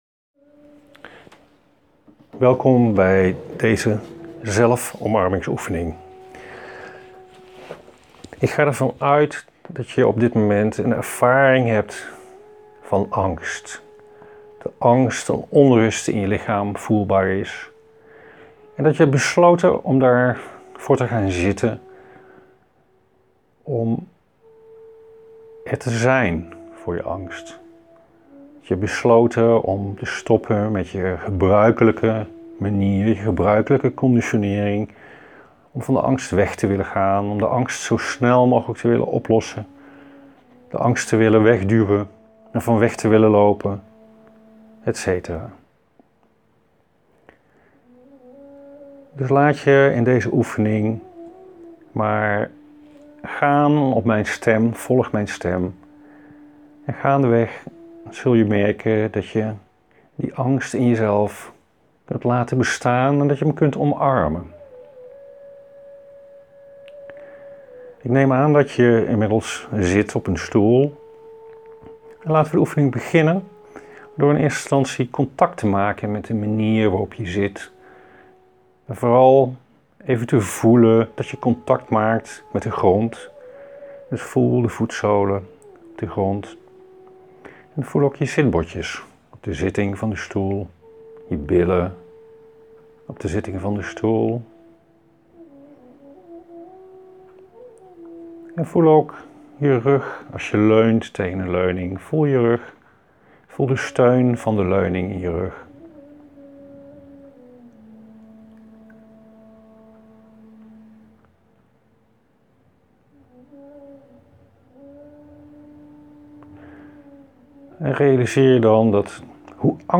De oefeningen
zelfomarmingsoefening-met-angst.mp3